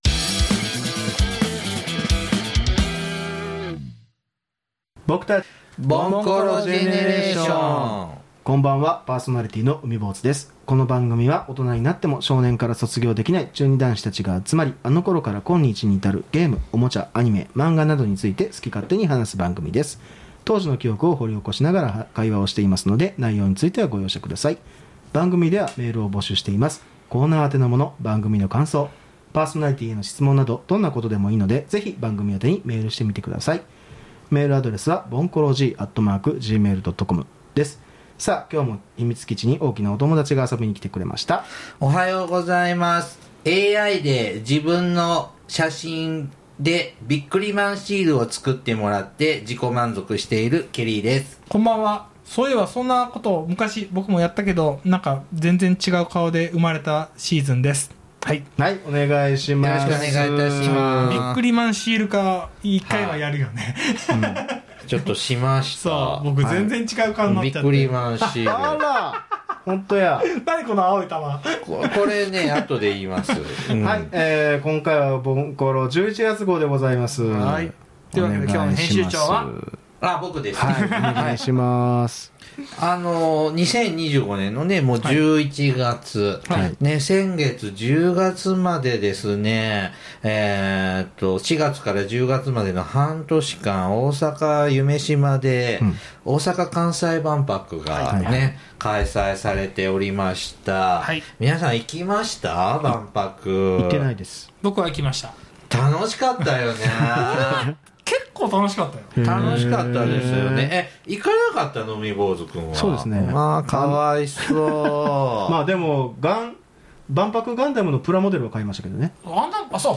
この番組は、大人になっても少年から卒業できない中二男子が集まり、あの頃から今日に至るゲーム・おもちゃ・アニメ・漫画などについて好き勝手に話す番組です。 過去の記憶を掘り起こしながら会話していますので、内容についてはご容赦下さい。